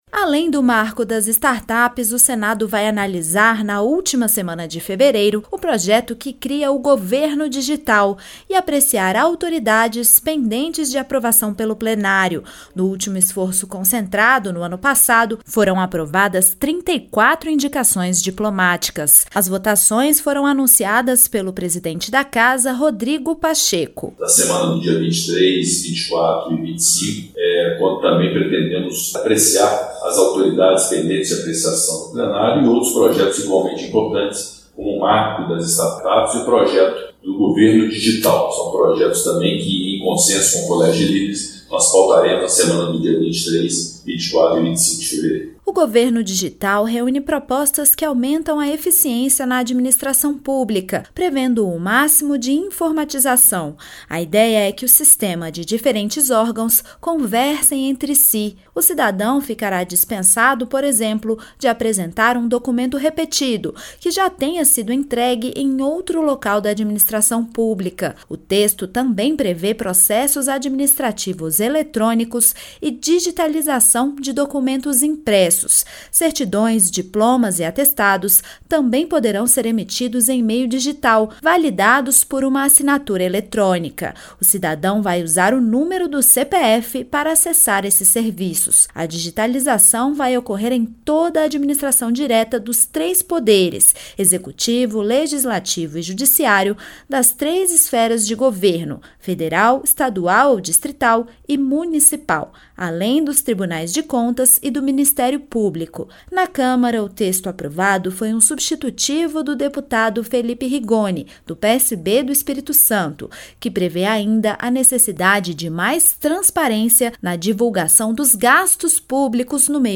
O presidente do Senado, Rodrigo Pacheco, informou que deverá pautar o projeto do Governo Digital na última semana de fevereiro, além de deliberar sobre indicações de autoridades pendentes de apreciação pelo Plenário. O texto permite o compartilhamento de documentos entre os órgãos da administração pública e a emissão de documentos como certidões, diplomas e atestados em meio digital. A reportagem